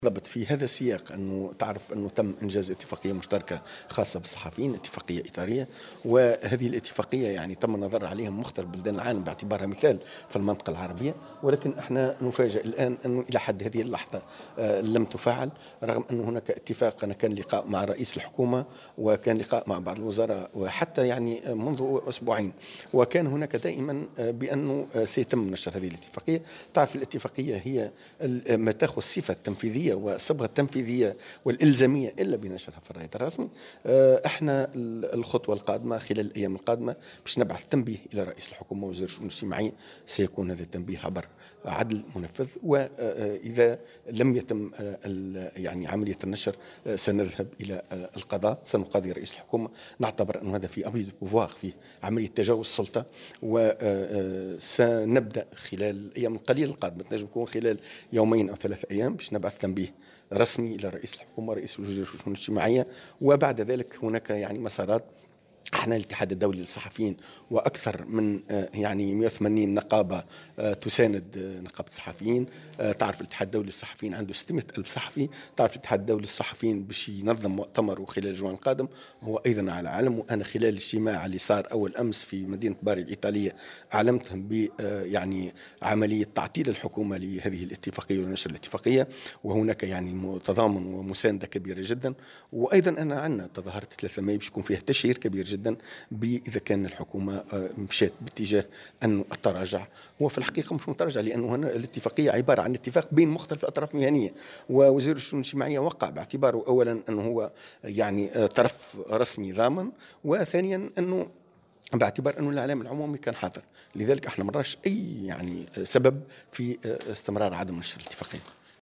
وأضاف في تصريح اليوم لمراسلة "الجوهرة أف أم" هلى هامش مشاركته في الندوة الإقليمية التي ينظمه مركز الدراسات المتوسطية والدولية حول الاعلام و الانتقال الديمقراطي في الوطن العربي بالحمامات، أنه سيتم خلال اليومين القادمين توجيه تنبيه رسمي عبر عدل منفذ الى رئيس الحكومة ووزير الشؤون الاجتماعية، ثم اللجوء إلى القضاء من أجل تفعيل هذه الاتفاقية.